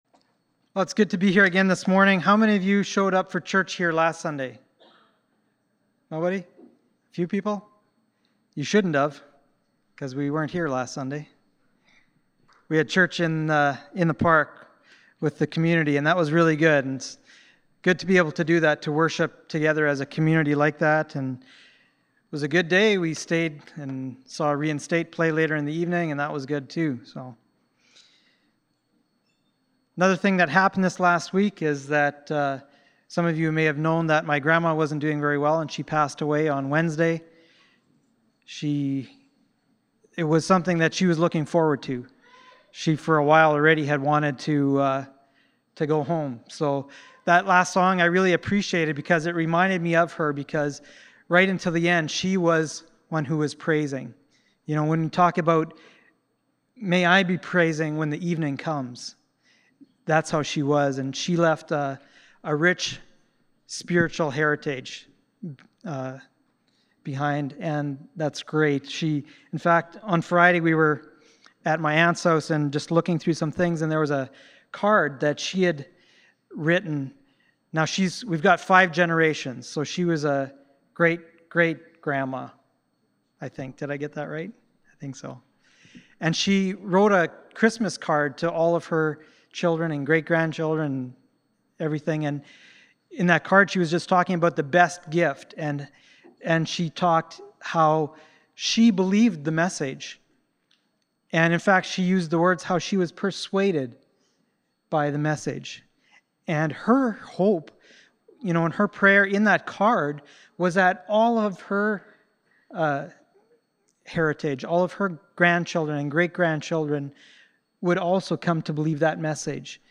Galatians 3:1-12 Service Type: Sunday Morning « Justice for Today